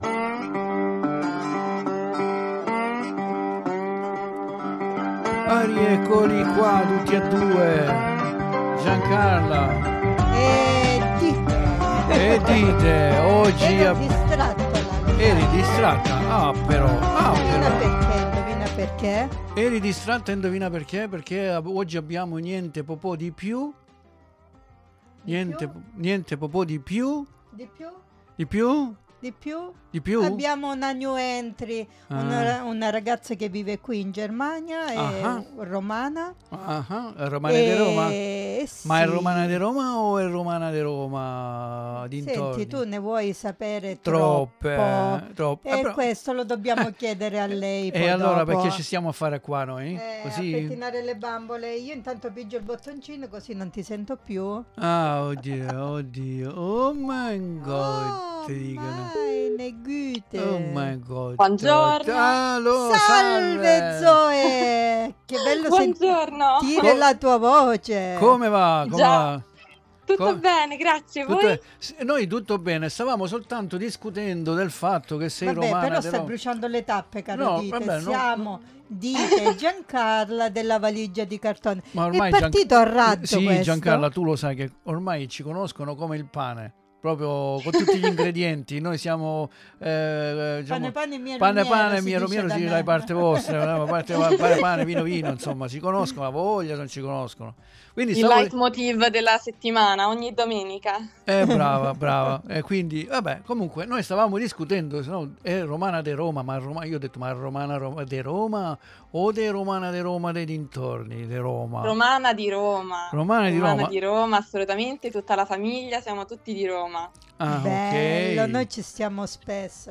PER SCOPRIRE DI PIÚ SULLA SUA STORIA E SUL SUO PERCORSO MUSICALE, TI INVITIAMO AD ASCOLTARE L'INTERVISTA CONDIVISA QUI NELLA DESCRIZIONE.